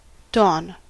dawn-us.mp3